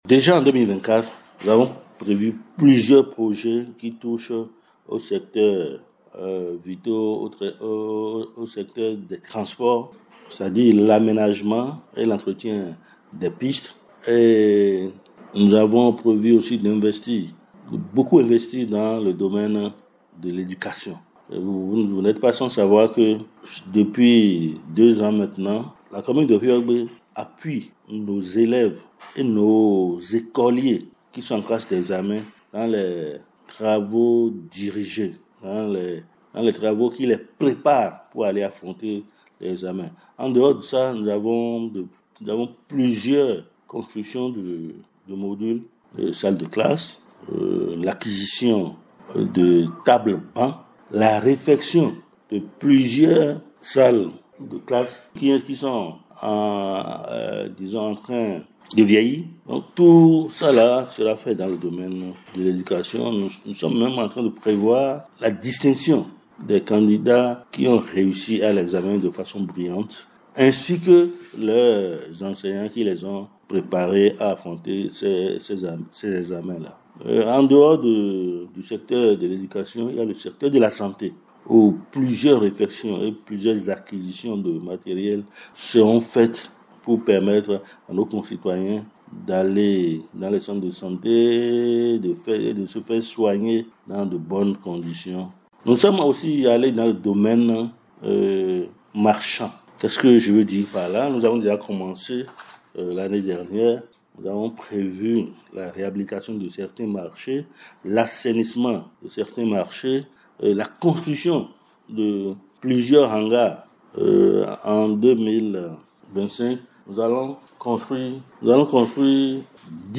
Le PAM de Houéyogbé, Casimir Sossa précise quelques actions à mener en 2025.